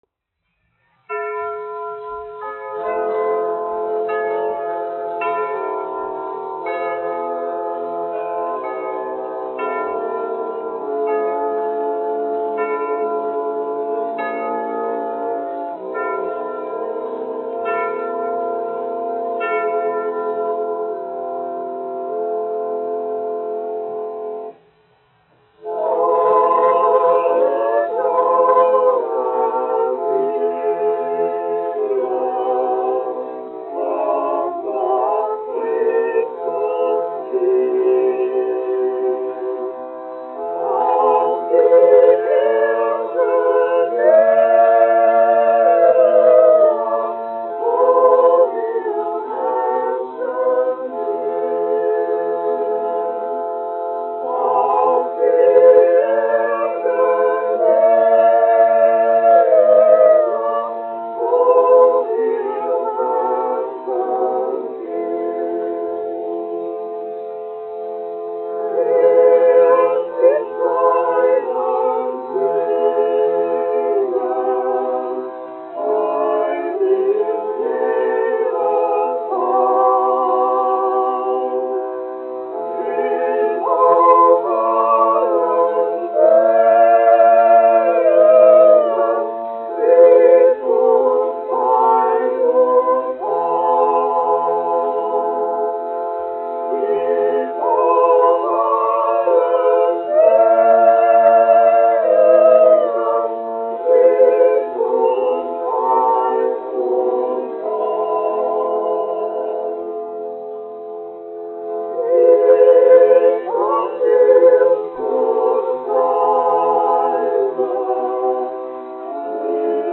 Königliche Hofoper (Berlīne, Vācija) Koris, izpildītājs
1 skpl. : analogs, 78 apgr/min, mono ; 25 cm
Ziemassvētku mūzika
Kori (jauktie)
Skaņuplate
Latvijas vēsturiskie šellaka skaņuplašu ieraksti (Kolekcija)